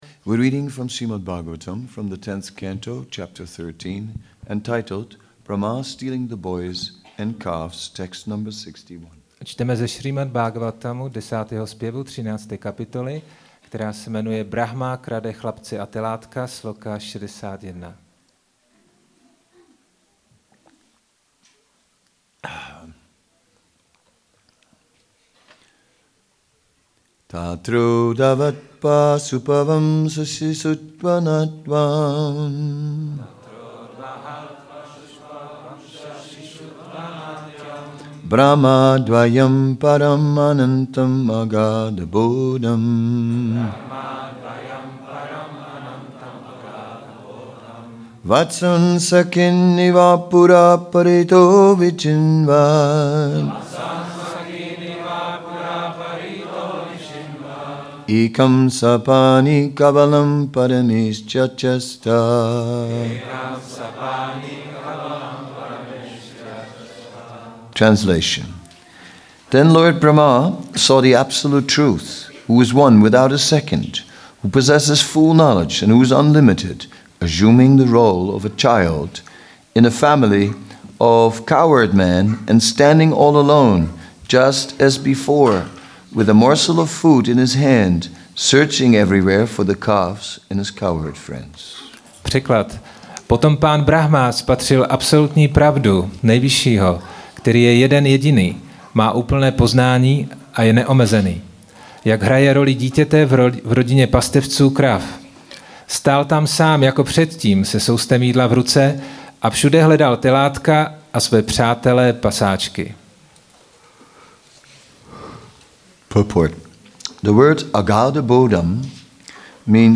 Přednáška SB-10.13.61 – Šrí Šrí Nitái Navadvípačandra mandir